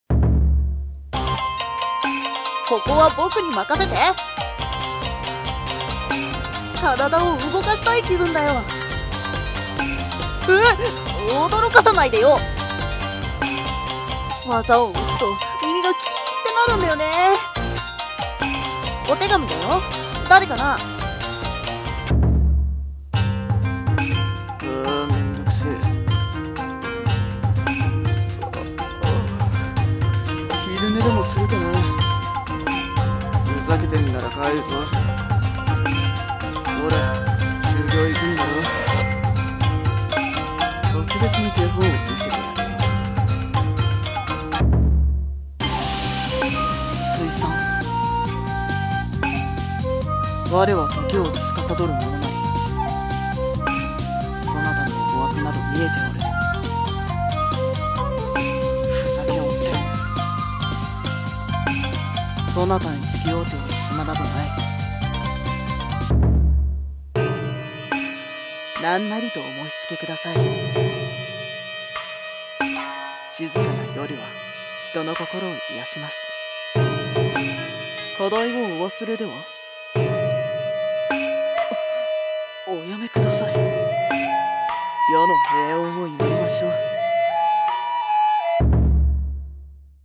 【演じ分け台本】妖怪格ゲーアプリ 女声/少年声用/後編【和風】